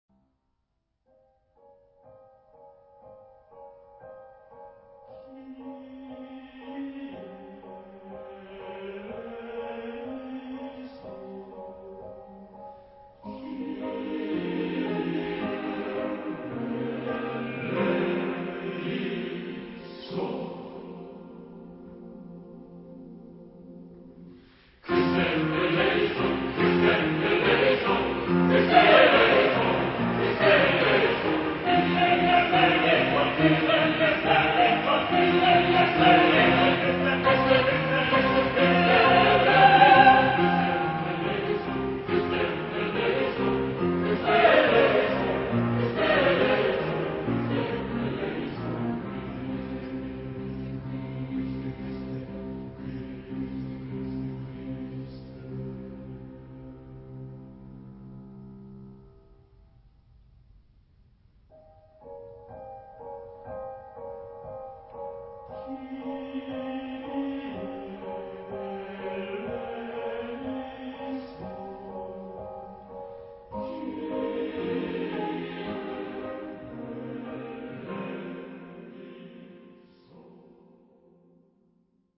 Sacré. contemporain. Messe.